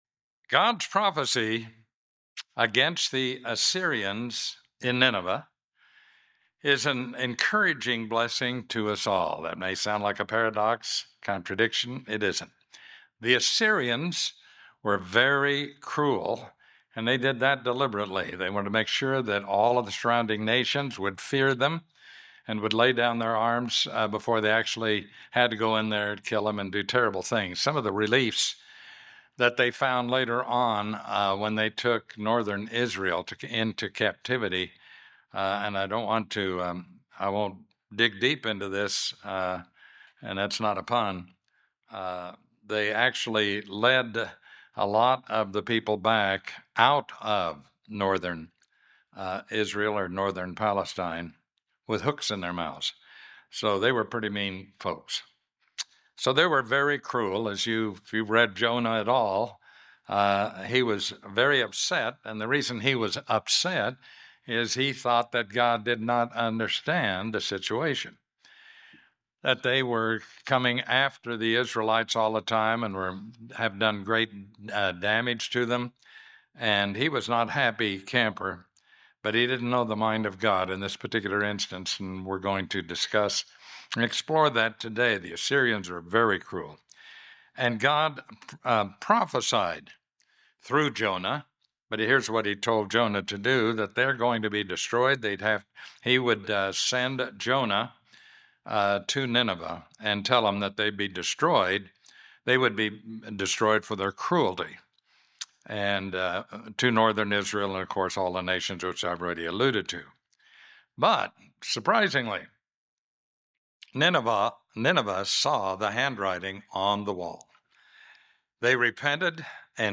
This sermon describes four beneficial purposes that God has when He records prophecies in the scriptures.